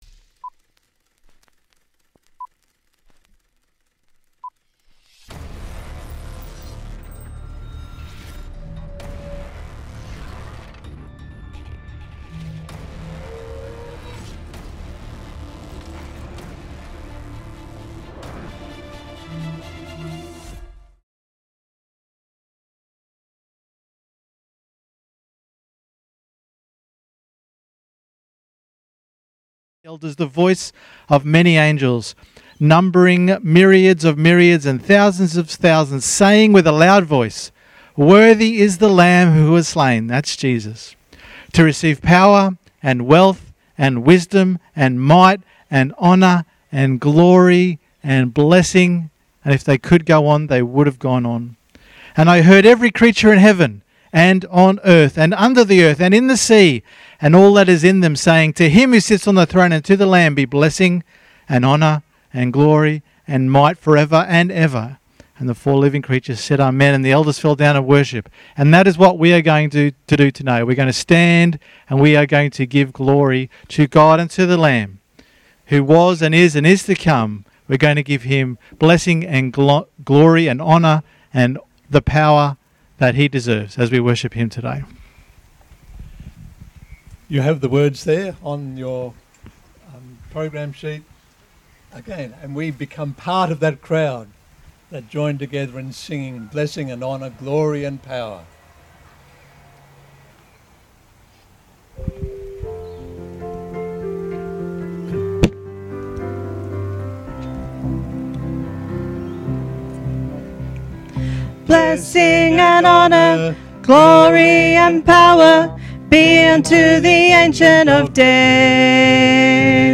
Sunday_Meeting_6th_September_2020_Audio.mp3